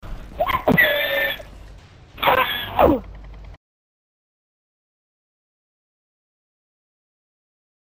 [egorgement de porcelet]